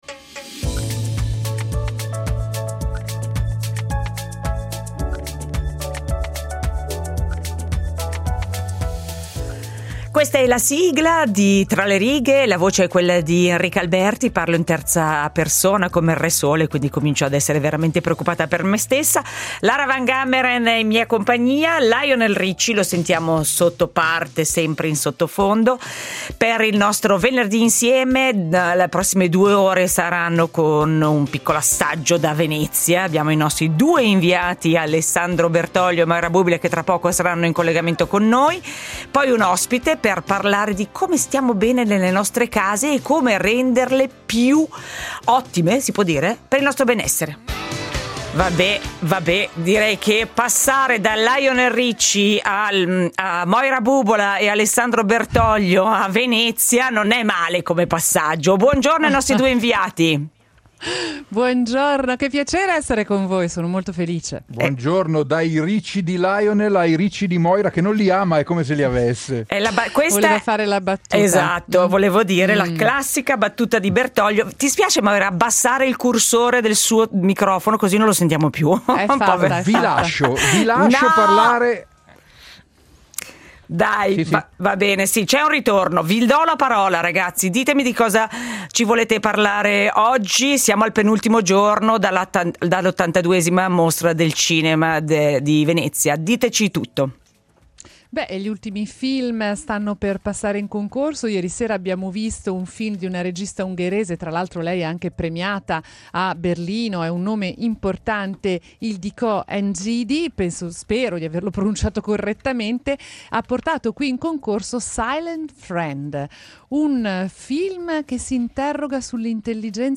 In diretta da Venezia
Primo appuntamento in diretta da Venezia per l’82ma Mostra Internazionale di arte cinematografica .